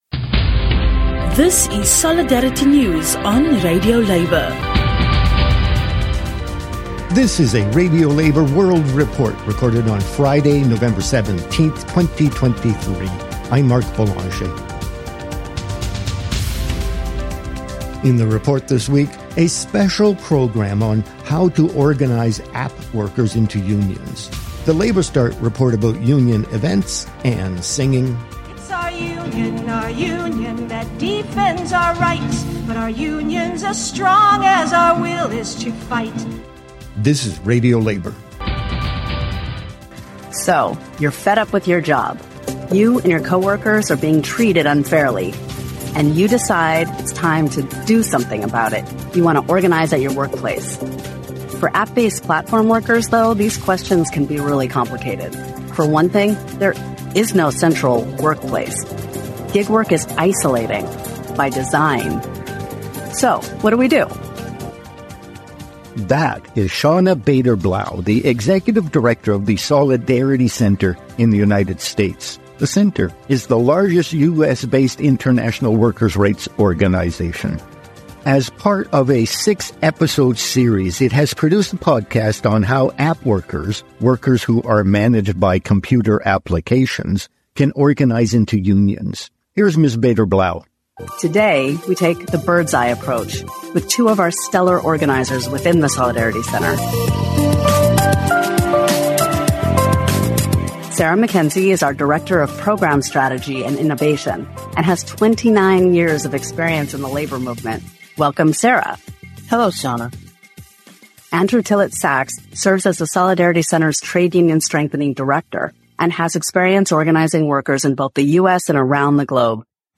And singing: 'Stand Up for Our Union'